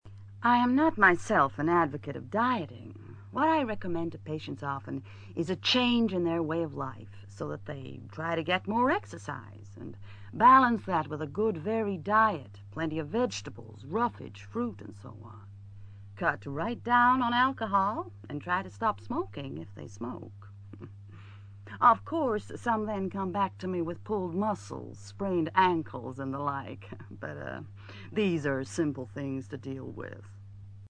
ACTIVITY 185: You are going to hear people from different professions talking about the boom in the 'Keep-Fit' industry.